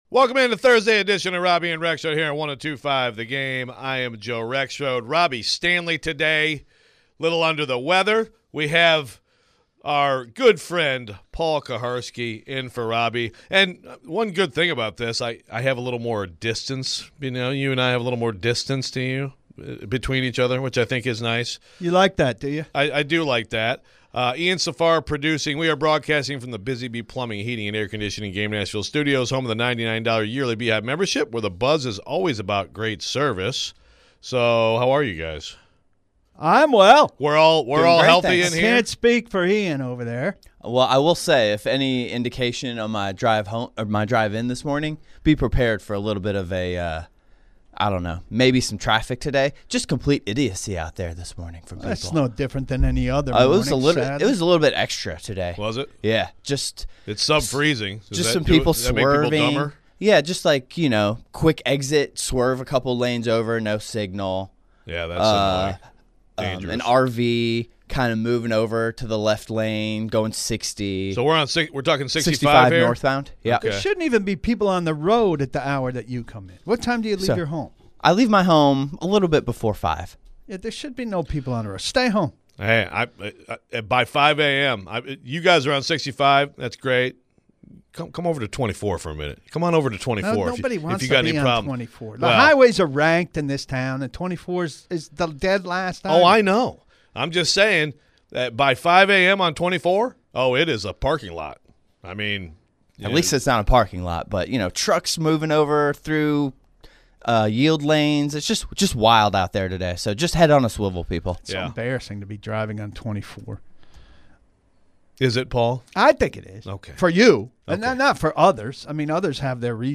We take your phones. We get into the latest with CFB coaching carousel.